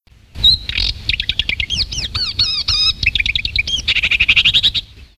Rousserolle verderolle, acrocephalus palustris
Le biotope dans lequel elle se situe et son chant original (elle peut imiter des dizaines d'autres passereaux) permettent à la distinguer.